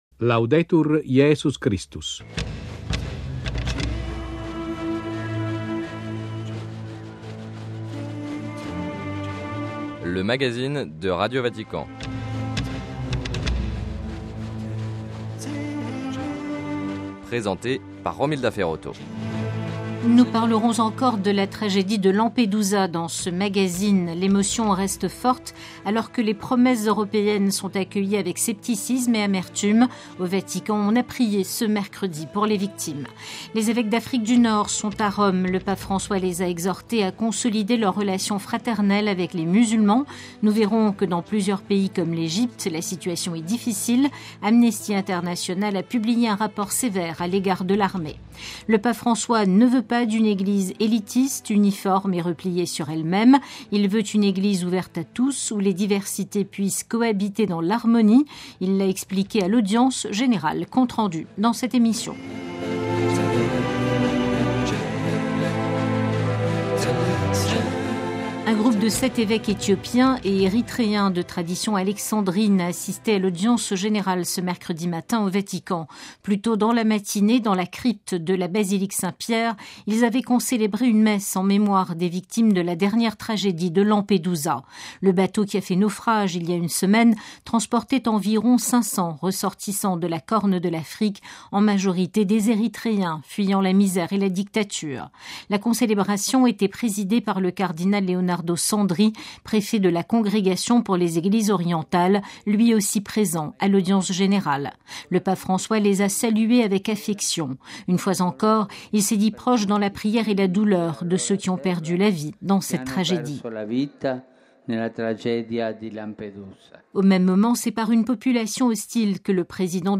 - Rapport d'Amnesty International sur les attaques contre les Coptes en Egypte. Entretien.
- Interview de Mgr Giorgio Bertin, évêque de Djibouti et administrateur apostolique de Mogadiscio.